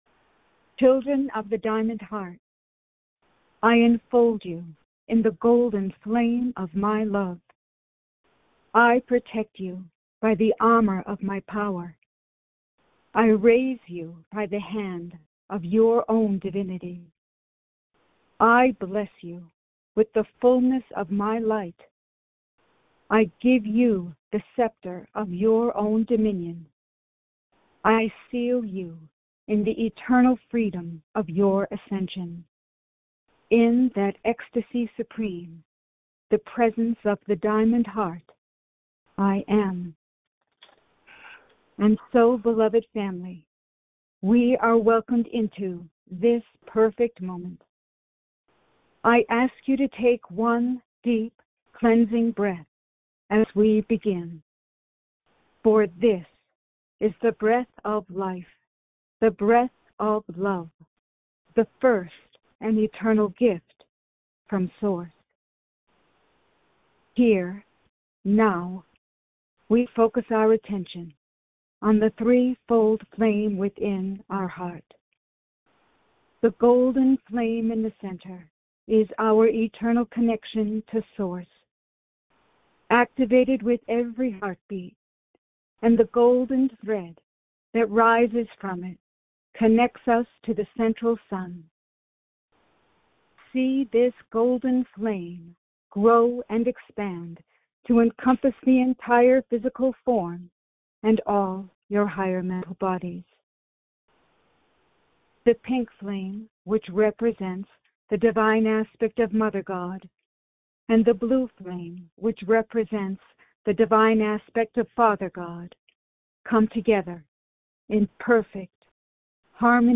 Follow along with Lord Sananda in group meditation.